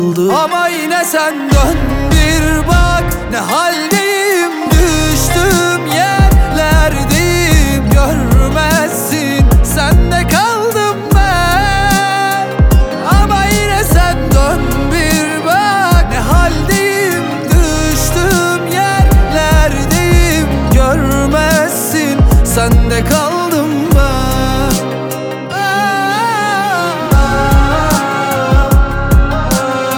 Жанр: Поп / Турецкая поп-музыка